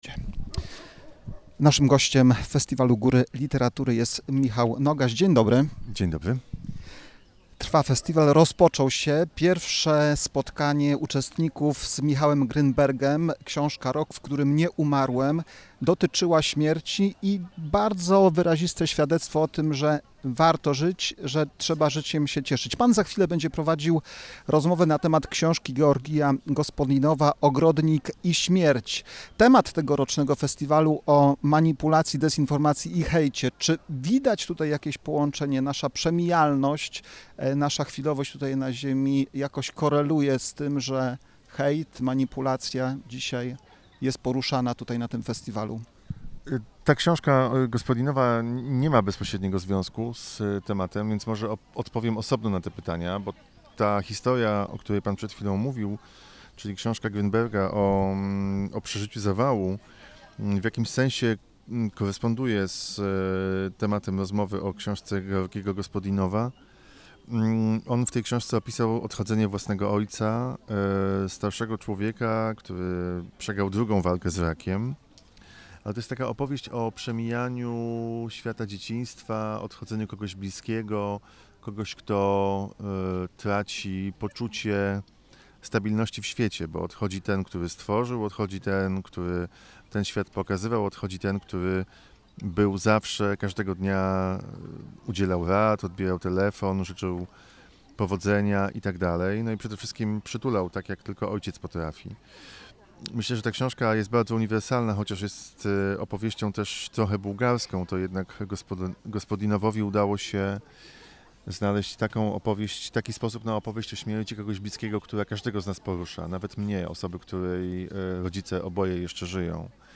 W audycji wspomniano również o dwóch książkach, które zostaną zaprezentowane podczas wydarzenia: